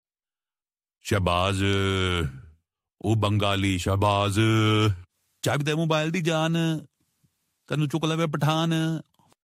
Goat Calling Shahbaz Name Sound Effects Free Download
Goat calling Shahbaz name